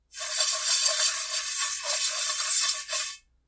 【効果音】フライパンを洗う - ポケットサウンド - フリー効果音素材・BGMダウンロード
washpan.mp3